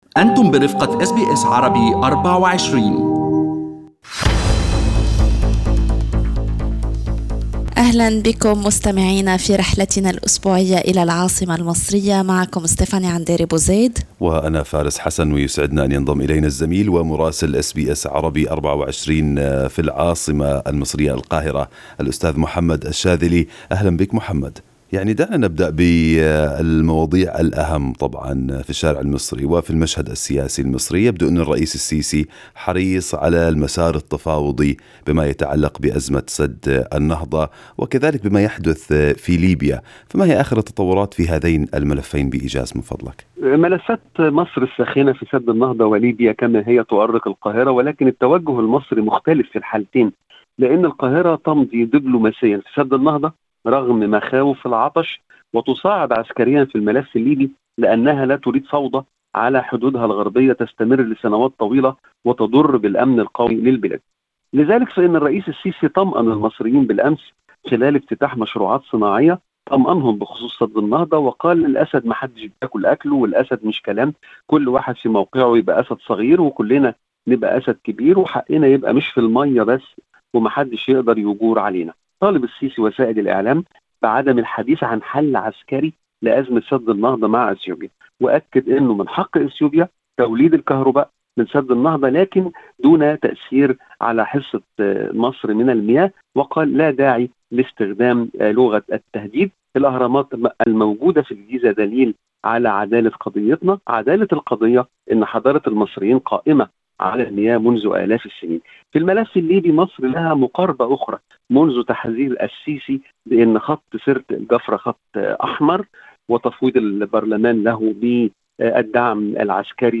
يمكنكم الاستماع إلى تقرير مراسلنا في مصر بالضغط على التسجيل الصوتي أعلاه.